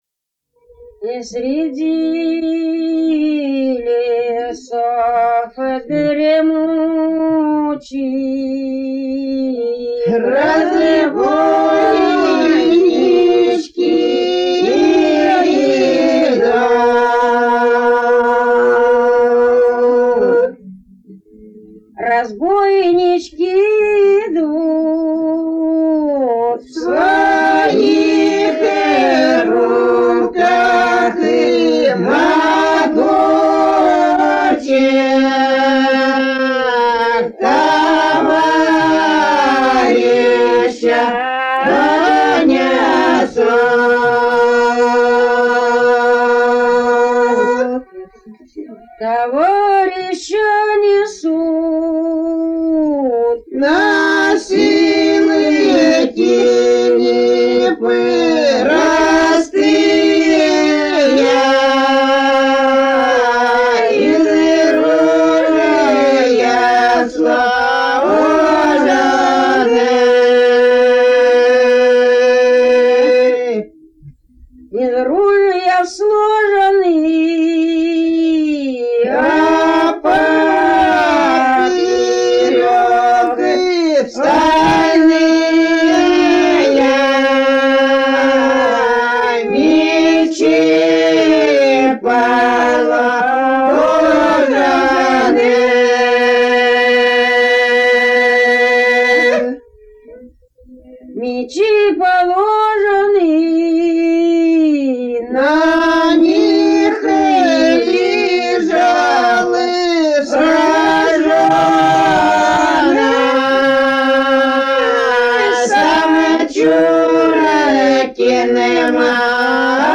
Народные песни Касимовского района Рязанской области «Среди лесов дремучих», разбойничья.